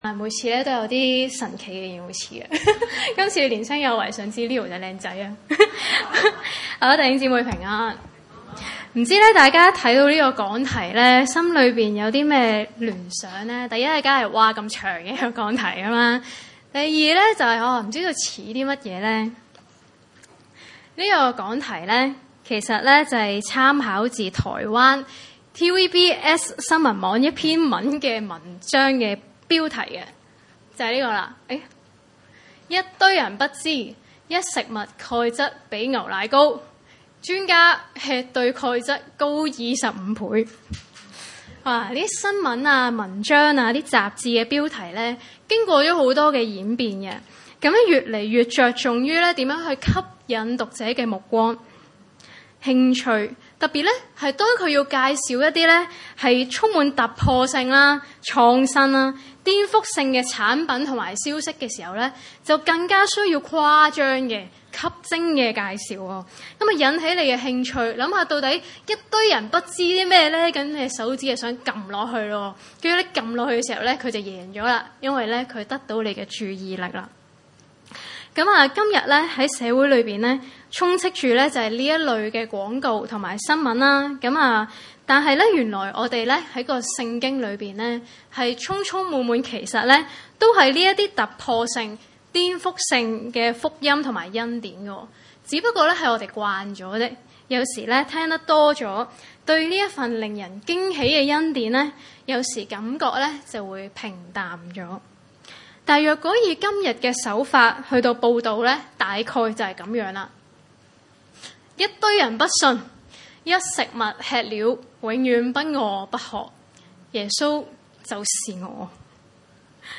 經文: 約翰福音 6:24-40 崇拜類別: 主日午堂崇拜 眾人見耶穌和門徒都不在那裏，就上了船，往迦百農去找耶穌。